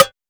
Snares
SNARE.91.NEPT.wav